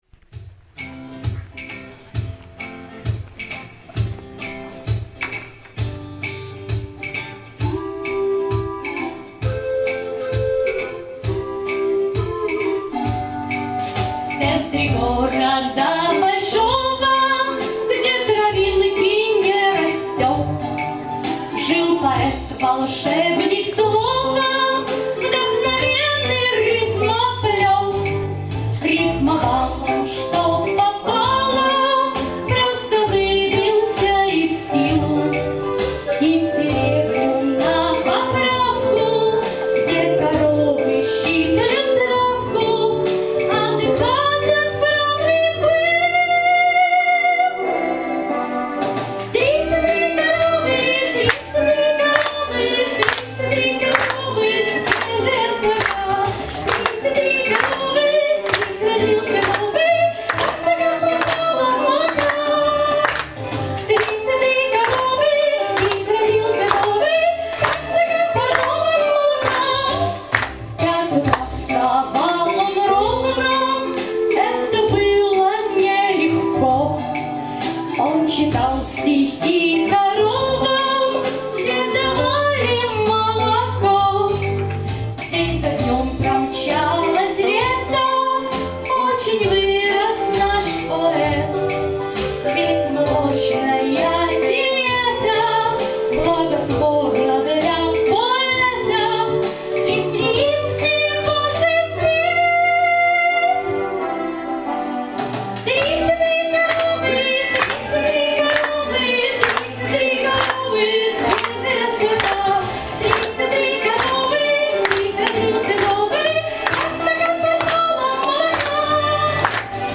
Живое звучание концерта
Вдохновение Детство Связь с природой Шуточная песня